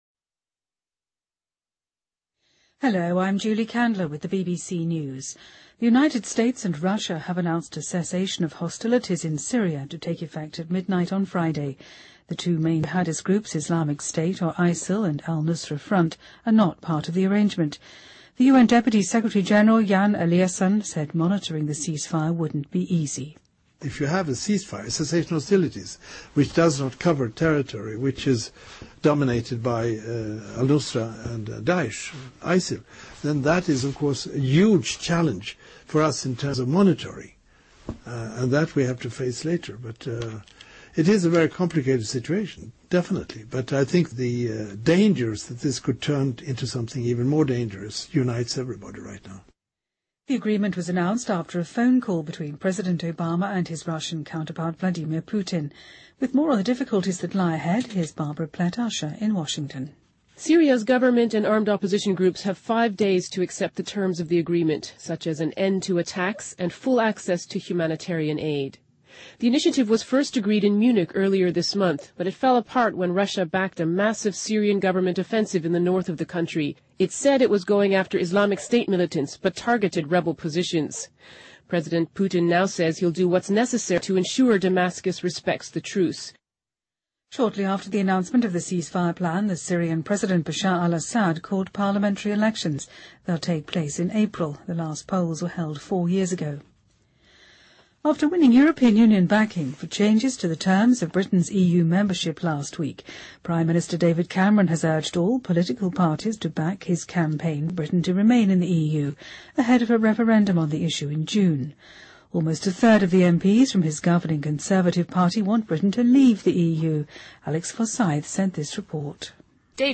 BBC news,:美俄达成叙利亚停火协议